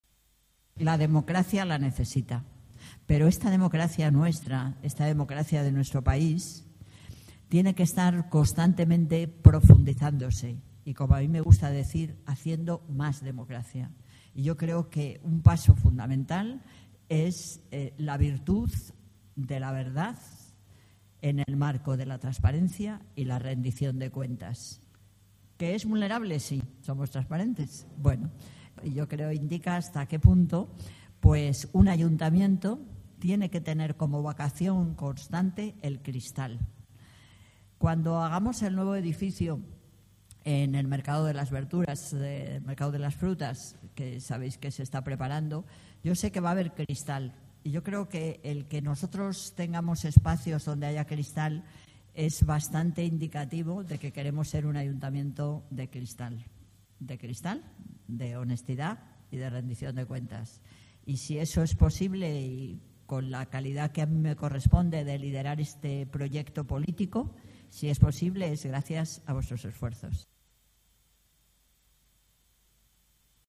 Medialab-Prado acoge las I Jornadas de Transparencia y Datos Abiertos del Ayuntamiento de Madrid
Nueva ventana:Manuela Carmena, alcaldesa de Madrid, en las I Jornadas de Transparencia y Datos Abiertos del Ayuntamiento de Madrid